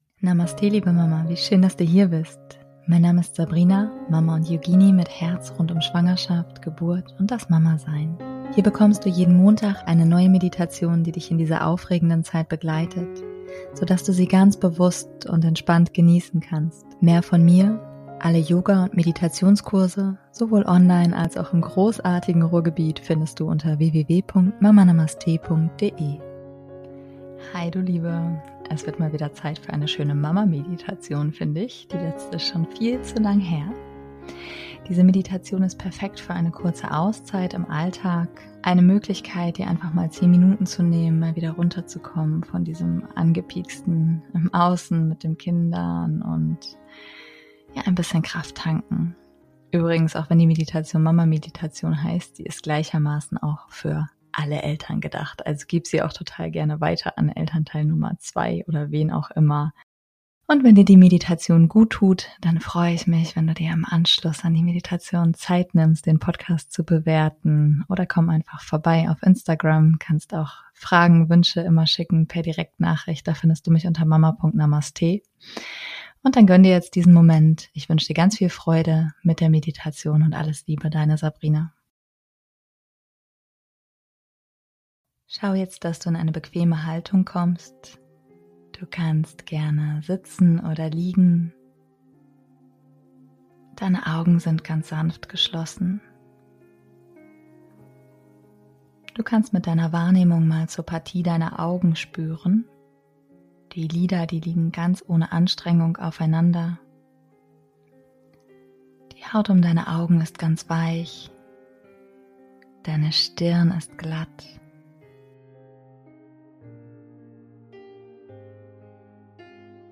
#149 - 10 Minuten Auszeit aus dem Mama Alltag - Meditation ~ Meditationen für die Schwangerschaft und Geburt - mama.namaste Podcast